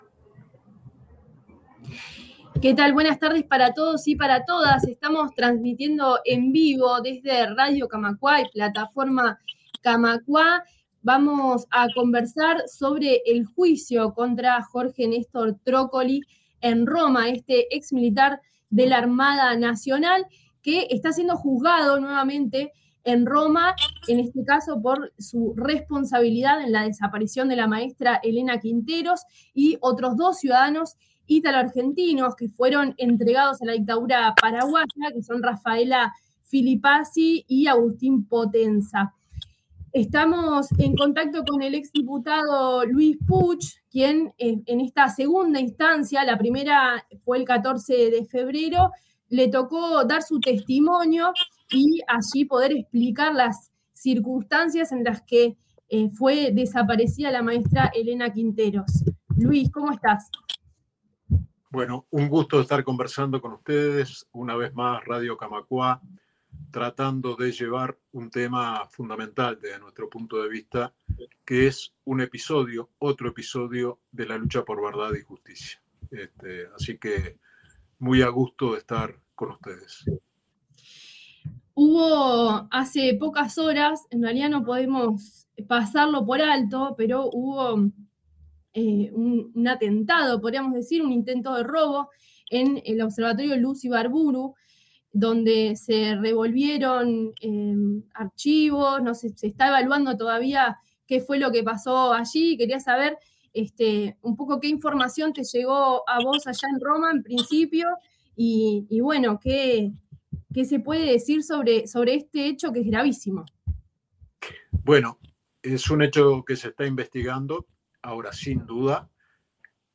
Entrevista con Luis Puig desde Roma